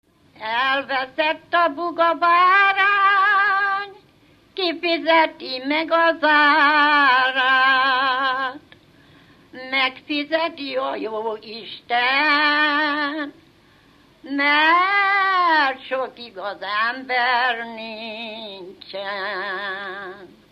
Alföld - Békés vm. - Gádoros
ének
Stílus: 3. Pszalmodizáló stílusú dallamok
Szótagszám: 8.8.8.8
Kadencia: 7 (b3) 4 VII